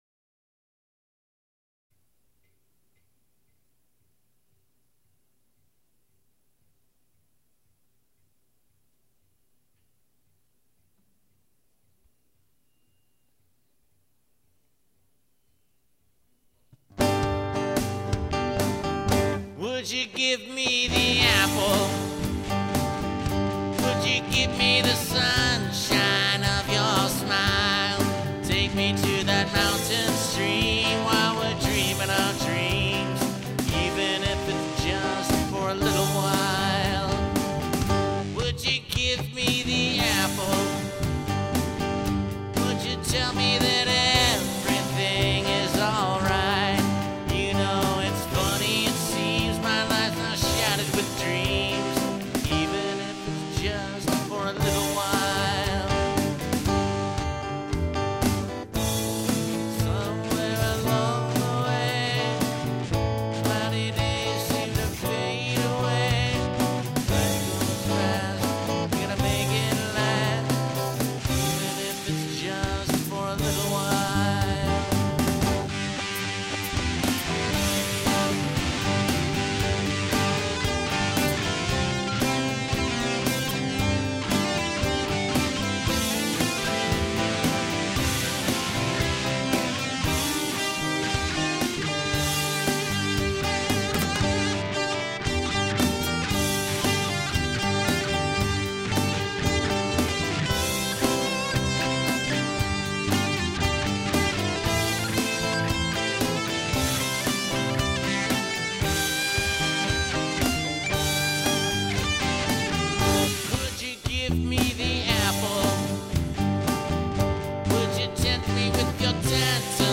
NOTE: The song begins about 15 seconds in.